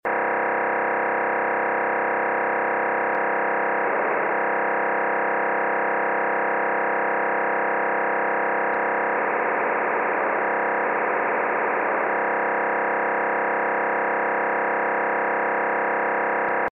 unknown signal